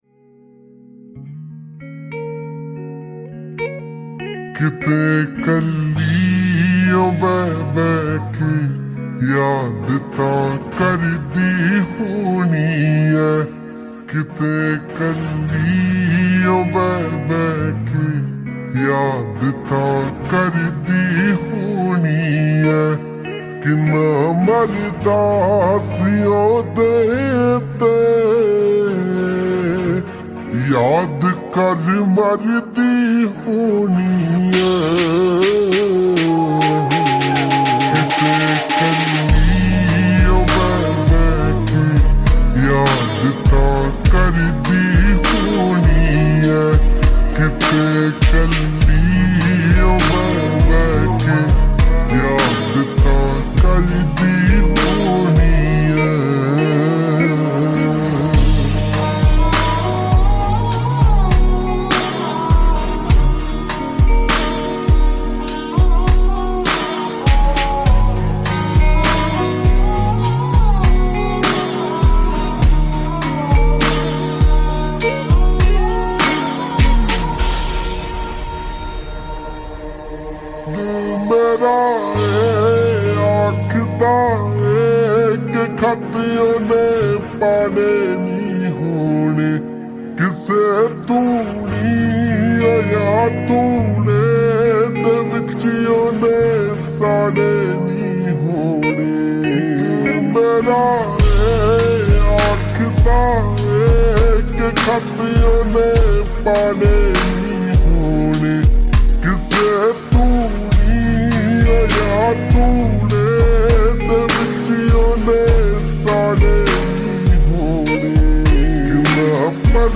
Slowed And Reverb